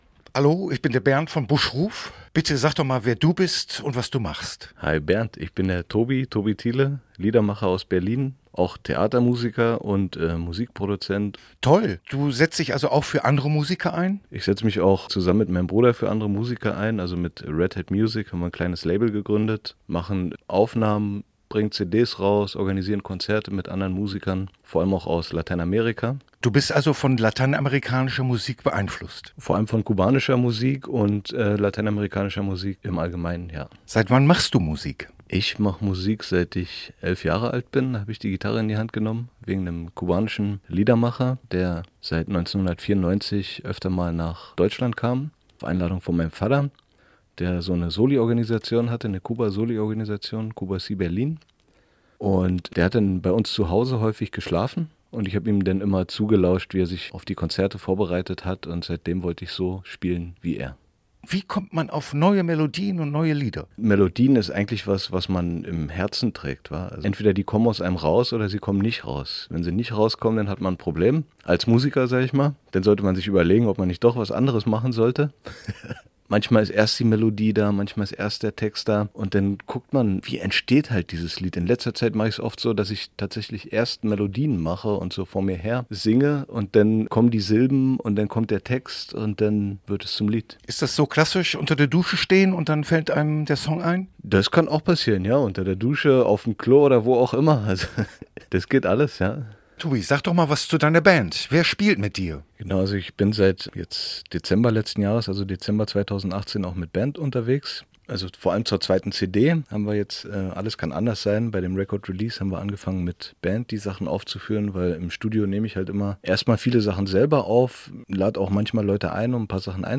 Musik